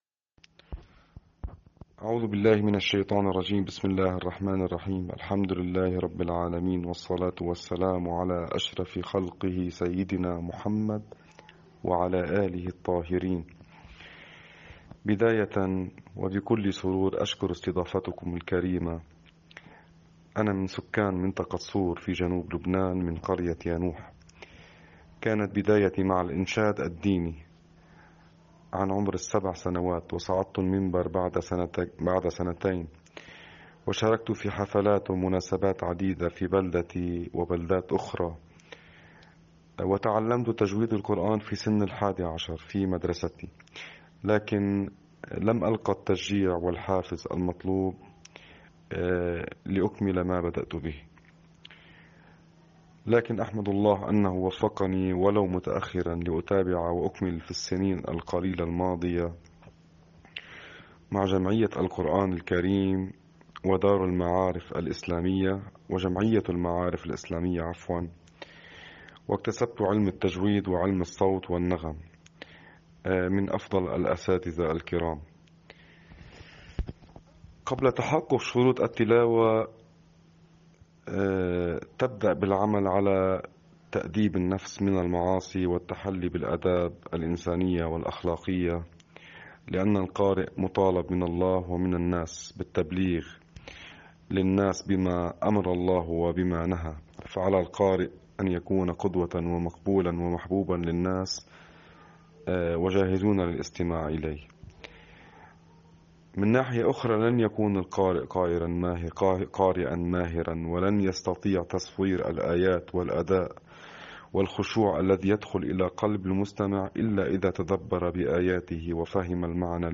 حواراً